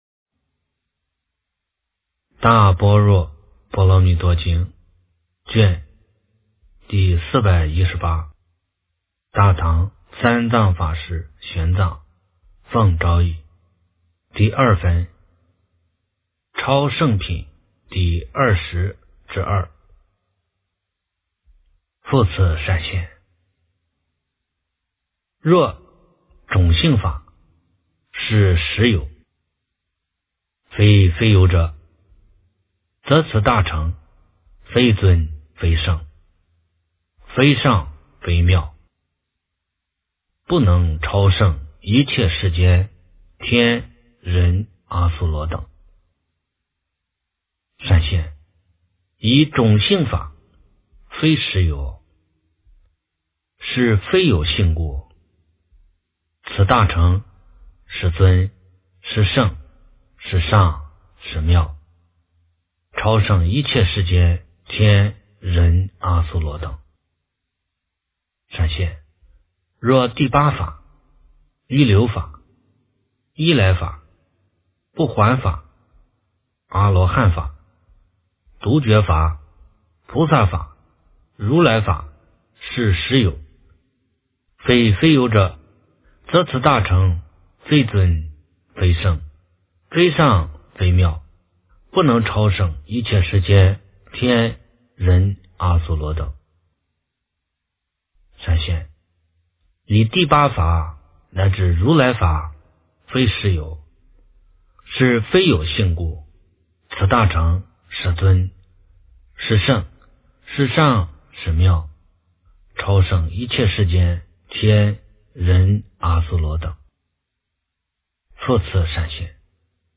大般若波罗蜜多经第418卷 - 诵经 - 云佛论坛